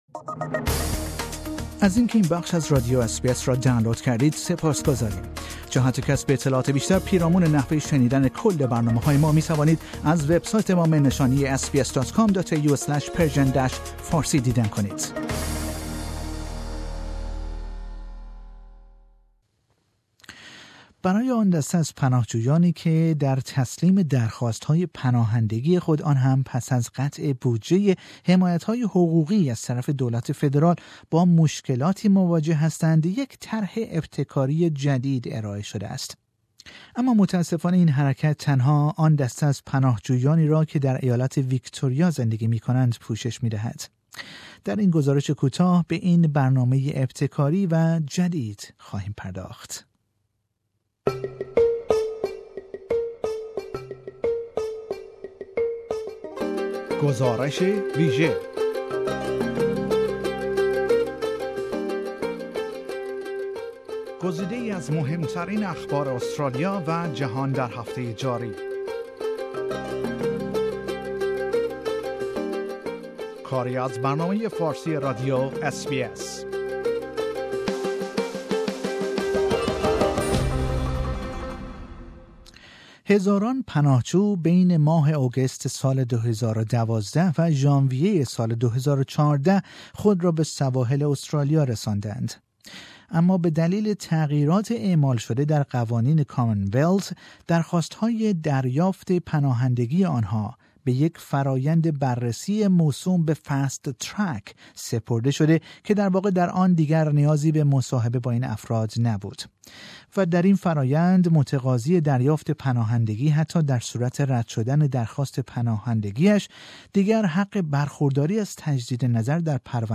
برای آن دسته از پناهجویانی که در تسلیم درخواست های پناهندگی خود آنهم پس از قطع بودجه حمایت های حقوقی از طرف دولت فدرال با مشکلاتی مواجه هستند، یک طرح ابتکاری جدید ارائه شده است. اگرچه این اقدام ابتکاری تنها آن دسته از پناهجویانی را که در ایالت ویکتوریا زندگی می کنند، پوشش می دهد، ما در این گزارش کوتاه به این برنامه ابتکاری و جدید پرداخته ایم.